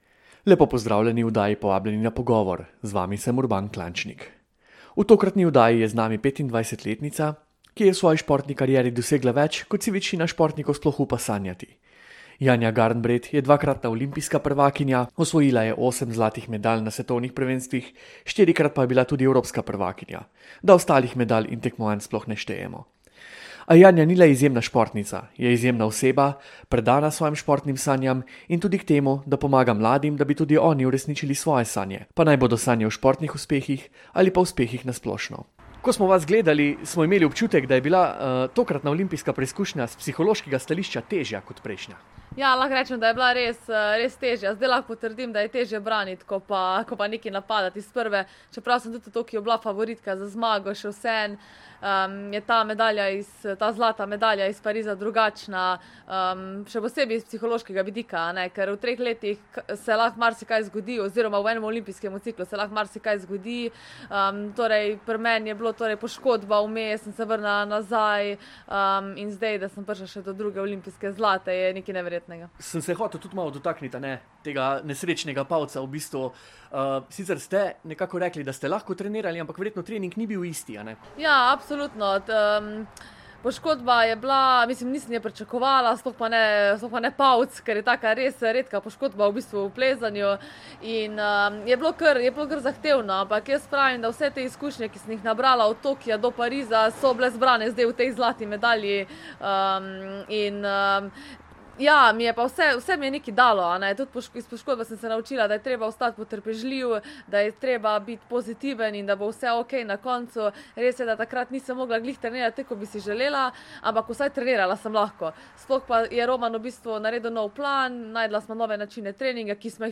Poslušajte celoten intervju z Janjo Garnbret.
Jajna Garnbret intervju za splet.mp3